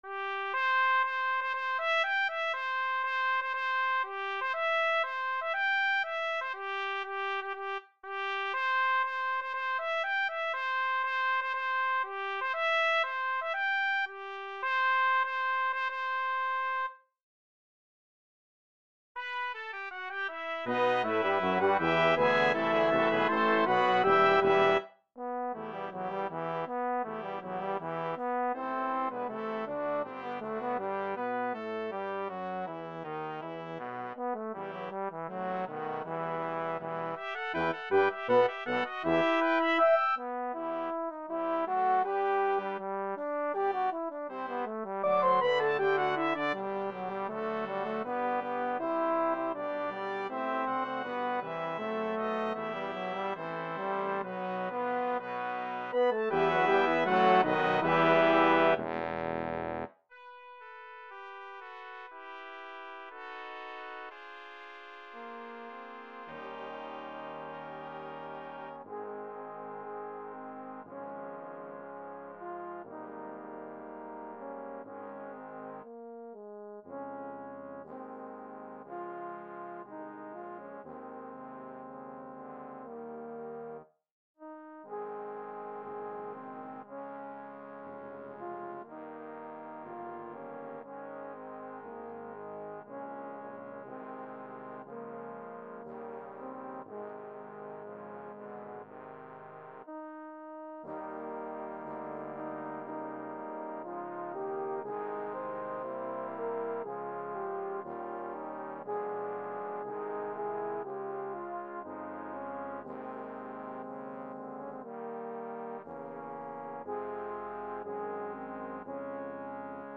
a medley of British sea songs